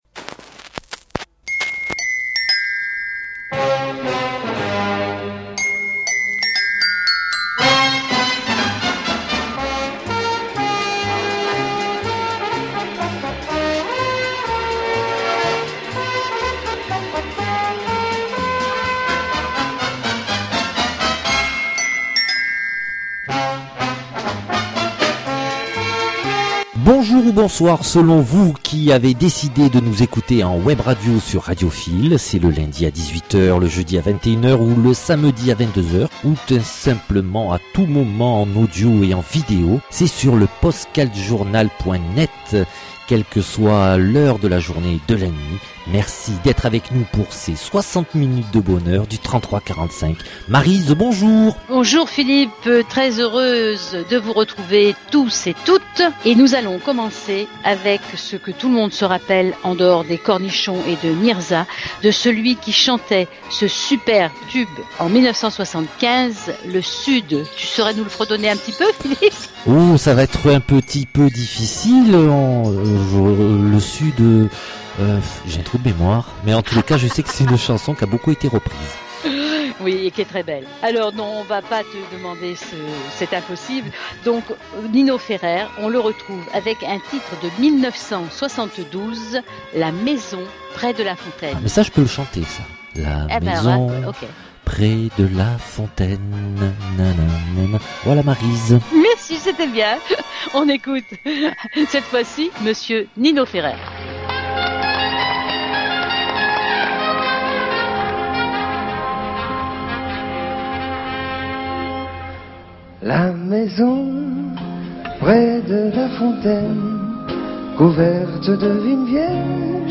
La différence, c'est de vous faire écouter les faces cachées passées aux oubliettes, des titres étouffés par les tubes de ces années vinyles. Revisiter cette fourmilière de chansons à textes sans autre prétention que l'envie de vous faire découvrir ou redécouvrir ces richesses, qui incroyablement, malgré le temps, restent intemporelles!